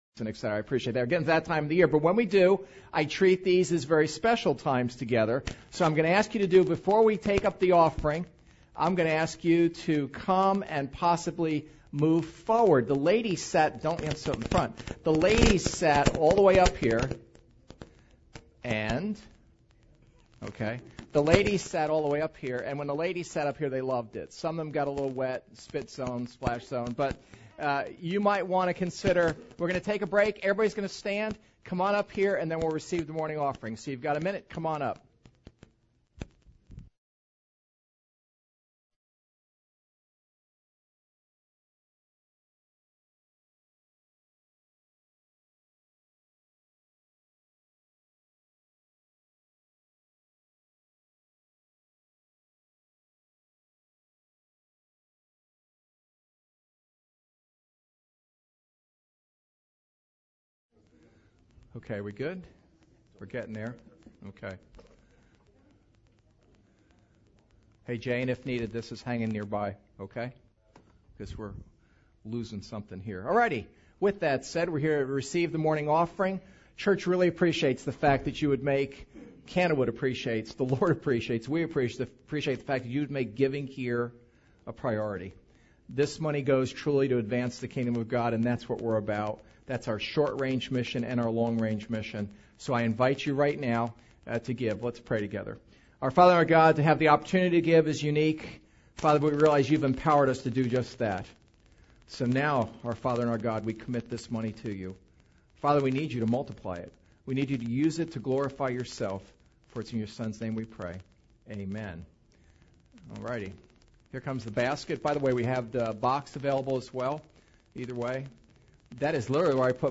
Philippians 4:8-9 Service Type: Sunday Service WHAT ARE YOU CHEWING ON?